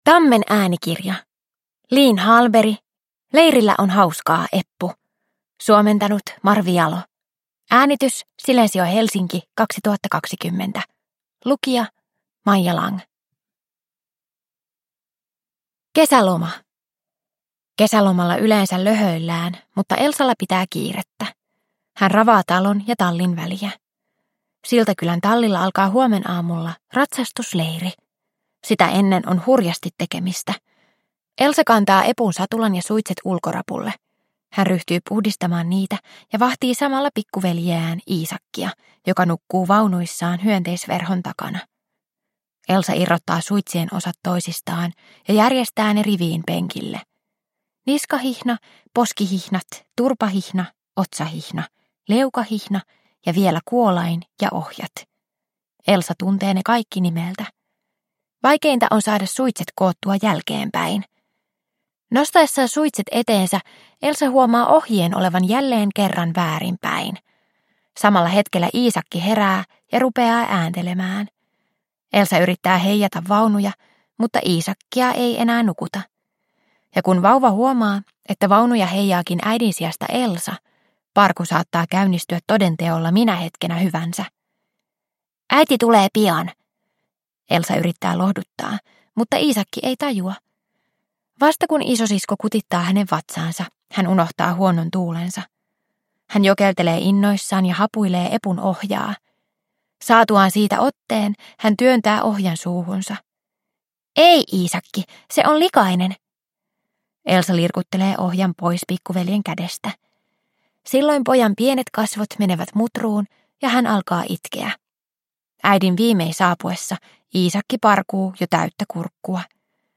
Leirillä on hauskaa, Eppu – Ljudbok